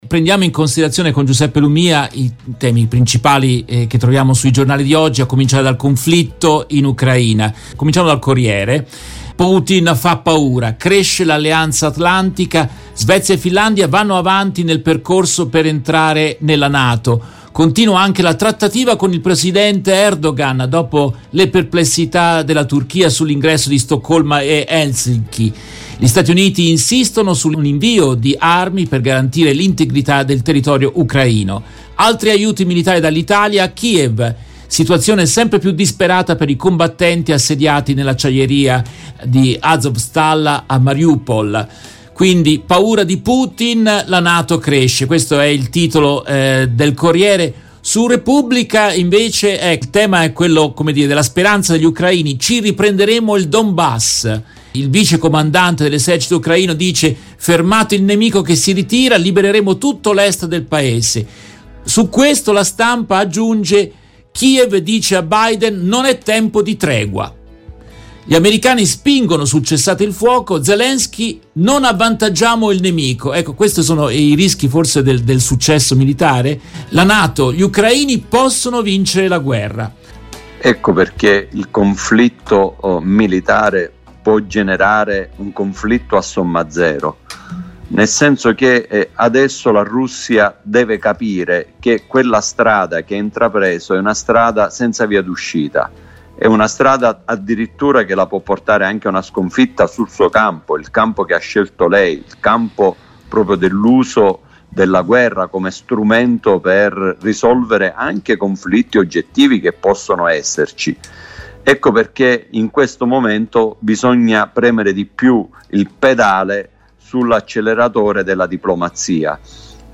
In questa intervista tratta dalla diretta RVS del 16 maggio 2022